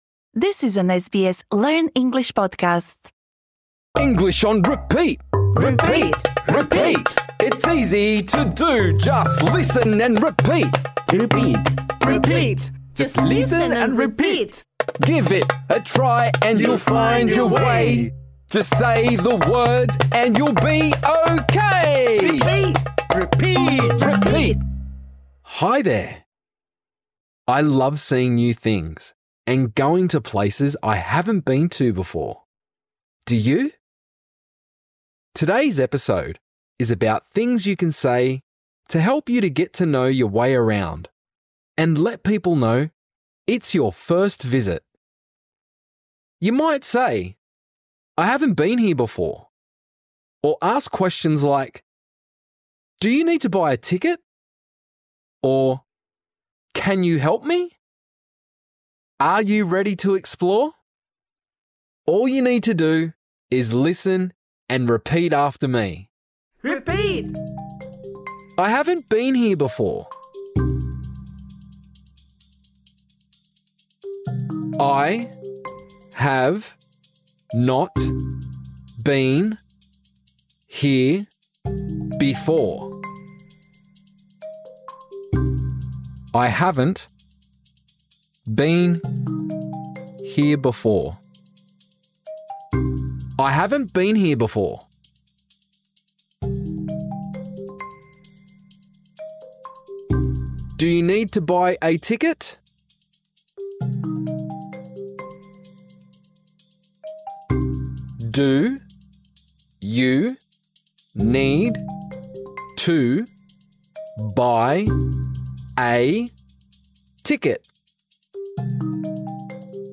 Цей урок розроблено для учнів початкового рівня володіння англійською мовою. У цьому епізоді ми практикуємо вимови наступних фраз: I haven’t been here before.